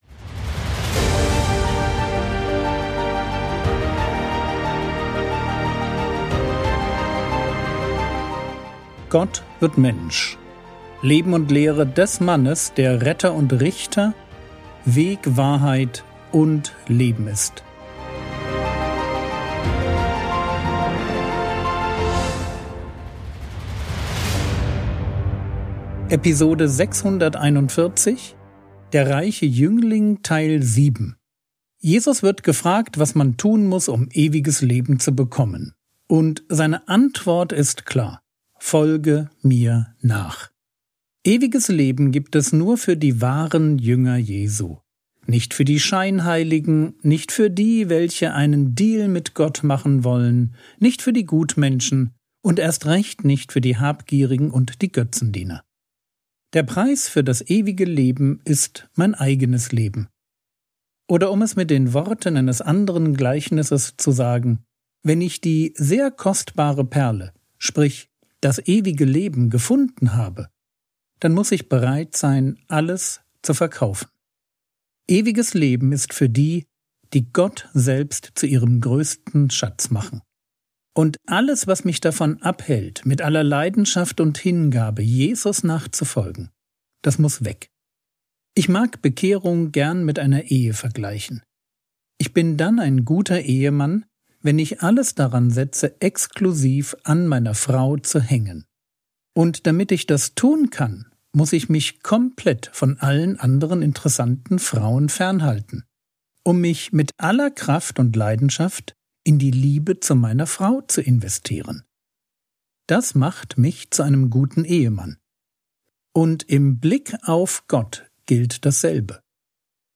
Episode 641 | Jesu Leben und Lehre ~ Frogwords Mini-Predigt Podcast